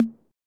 Index of /90_sSampleCDs/Roland - Rhythm Section/DRM_Drum Machine/KIT_TR-808 Kit
DRM 606 TO0Q.wav